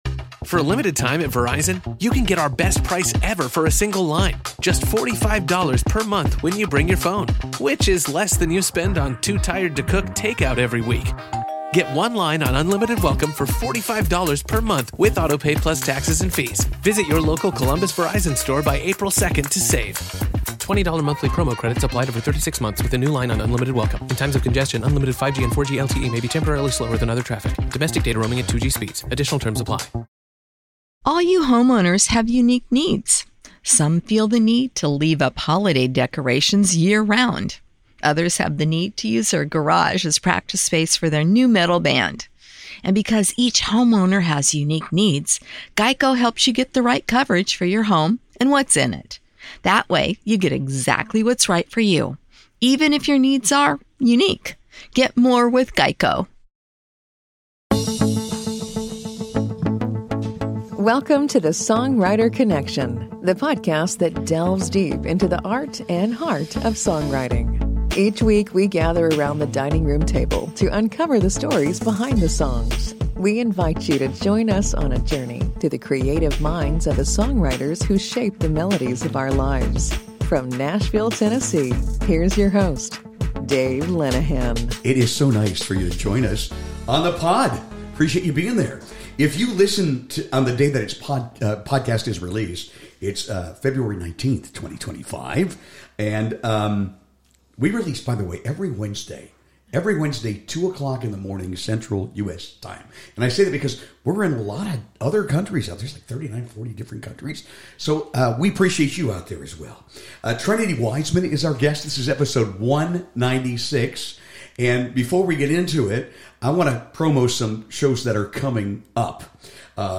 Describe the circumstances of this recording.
Plus, she treats us to a special live performance around the dining room table, where the heart of her music truly shines.